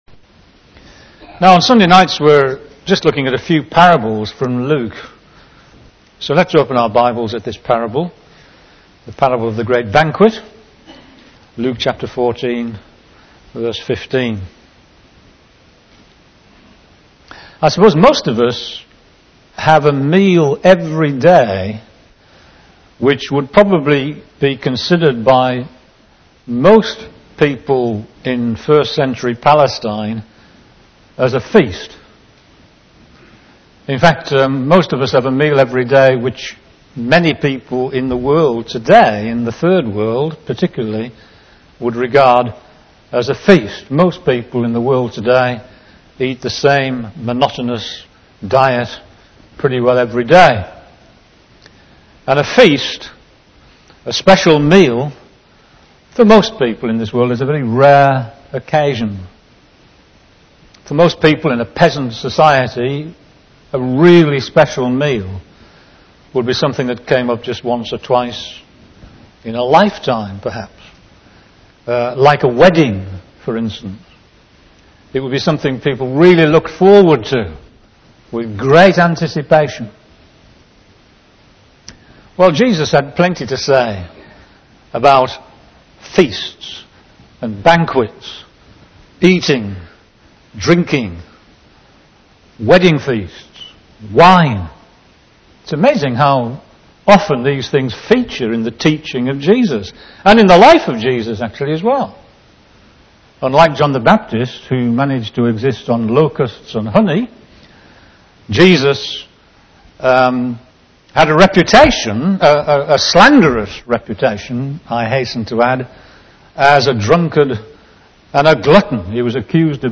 Sermons and Talks recorded at Parr Street Evangelical Church, Kendal, Cumbria, UK